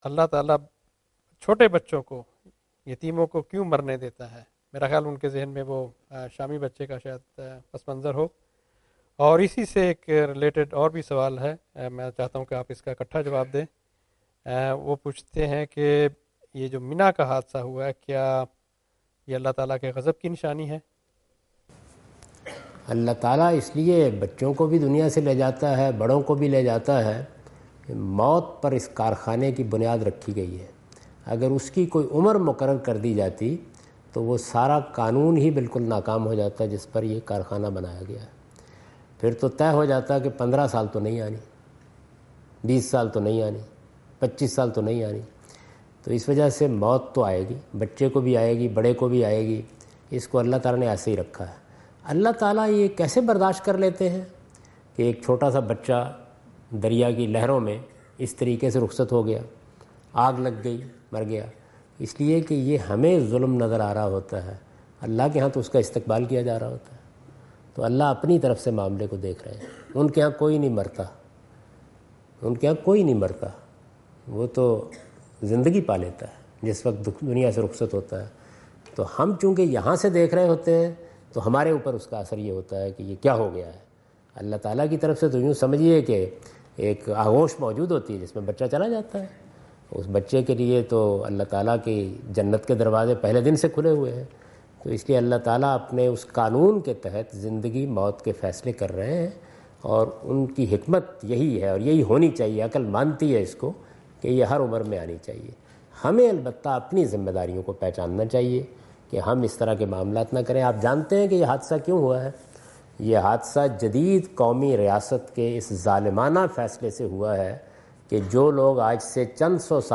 Javed Ahmad Ghamidi answer the question about "death of children: is it God's wrath?" in Macquarie Theatre, Macquarie University, Sydney Australia on 04th October 2015.
جاوید احمد غامدی اپنے دورہ آسٹریلیا کے دوران سڈنی میں میکوری یونیورسٹی میں "بچوں کی اموات، کیا یہ اعذاب الہی ہے؟" سے متعلق ایک سوال کا جواب دے رہے ہیں۔